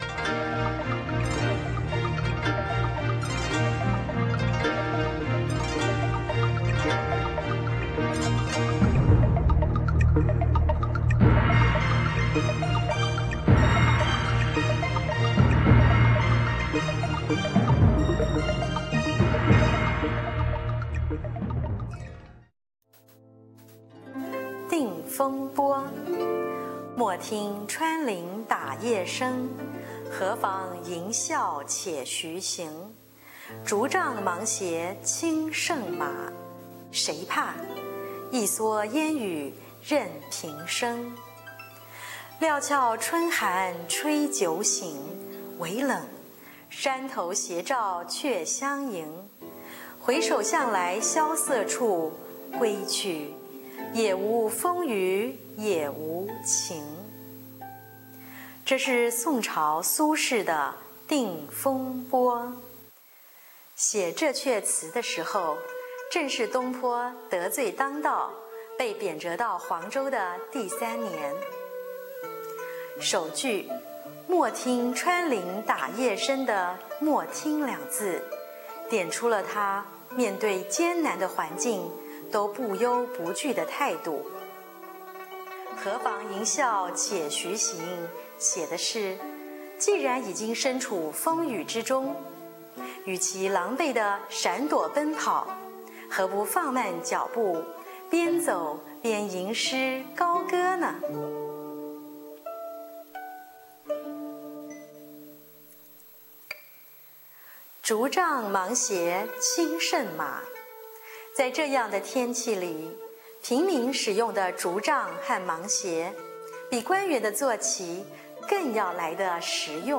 女兒回來，教我一招， 自吹自唱，作成影片，再傳上網， 好在我非大人物，沒什麼怕笑話的， 純粹好玩，沒有壓力， 閒嗎 ?